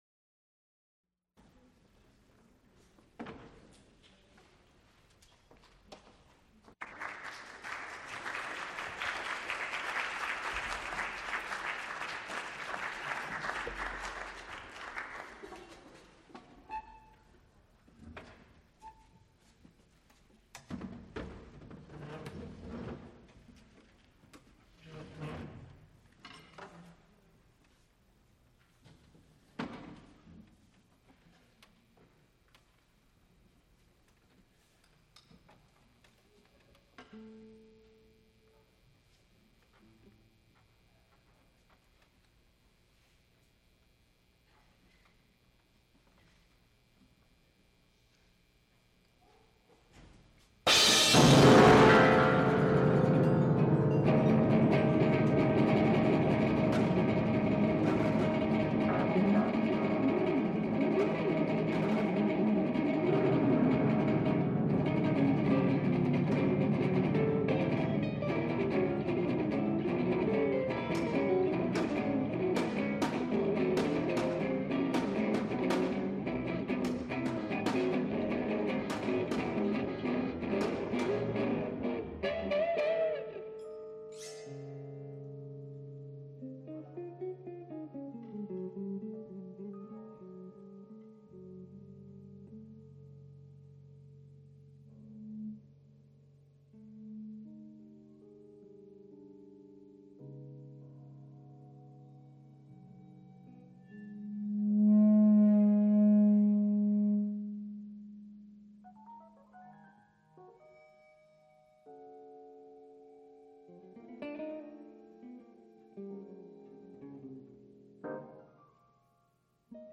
Concert of new music, improvisation, dance, and theatre.
Recorded live March 25, 1980, Frick Fine Arts Auditorium, University of Pittsburgh.
Extent 2 audiotape reels : analog, quarter track, 7 1/2 ips ; 12 in.
Instrumental ensembles